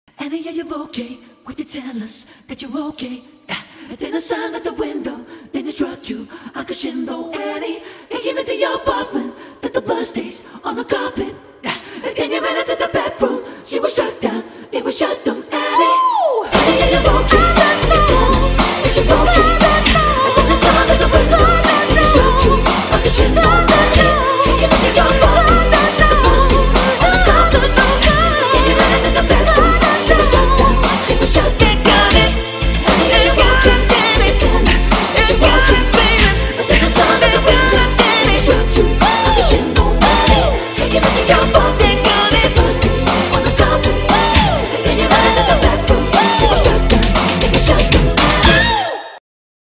Harmony Breakdown with instrumental